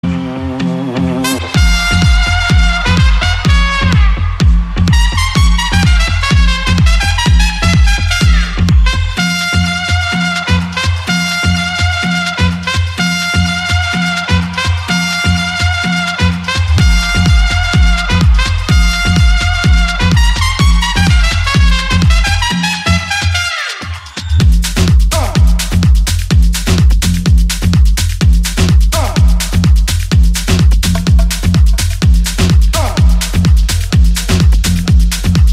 Kategori Marimba Remix